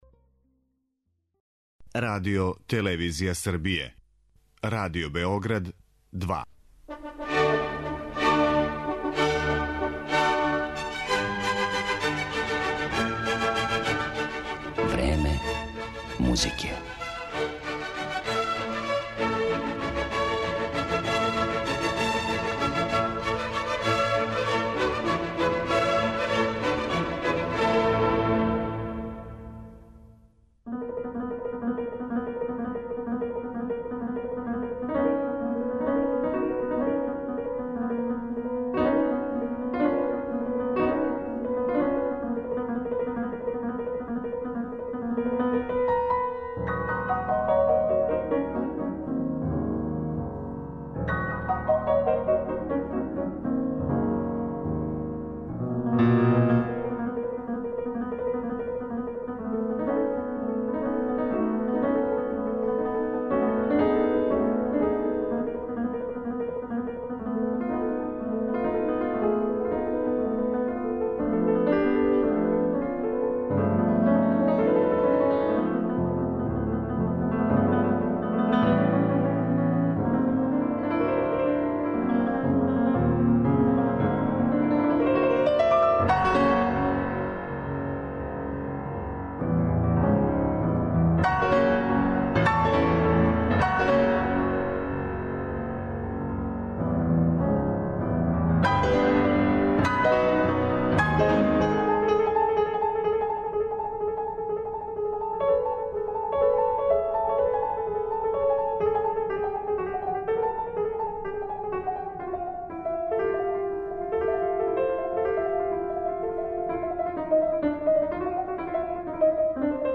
Мишел Далберто је један од најзначајнијих француских пијаниста данашњице.
интроспективни и помало херметички пијанизам, дистанцираност и рафинираност музичког израза, деликатност и исполираност звука